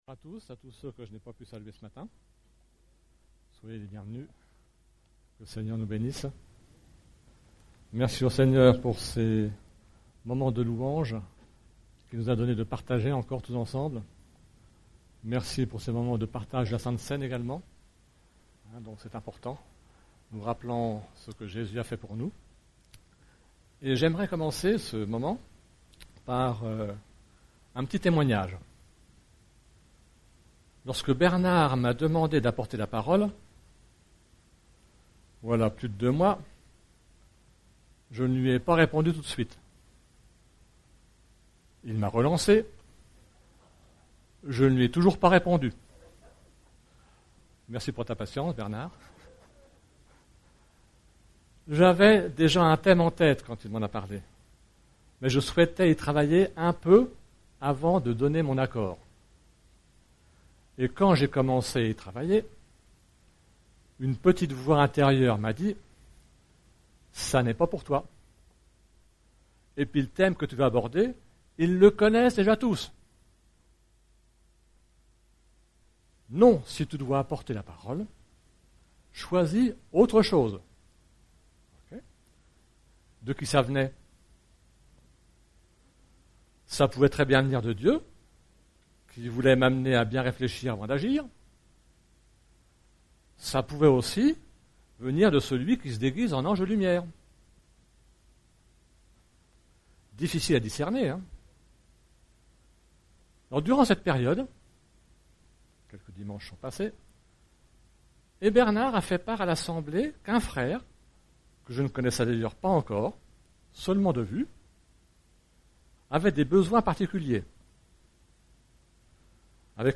Type De Service: Culte Thèmes: Relations fraternelles , Unité , Vie d'église « Ebed-Mélek